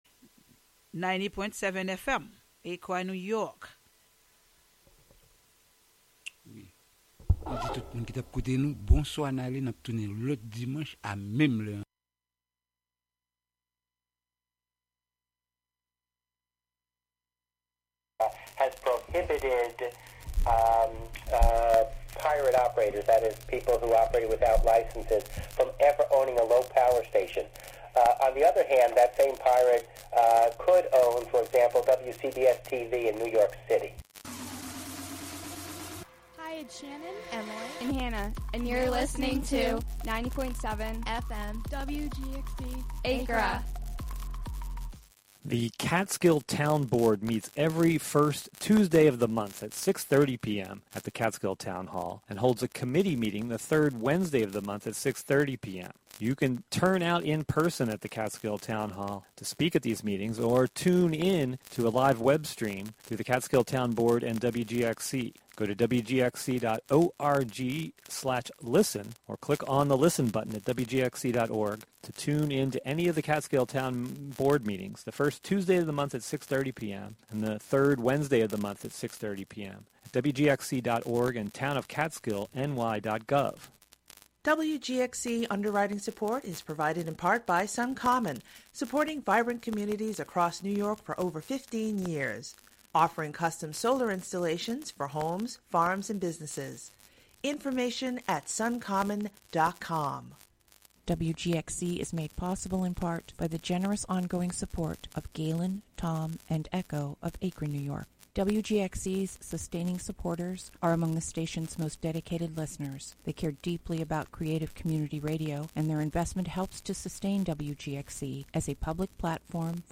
Broadcast live from the WGXC Hudson studio.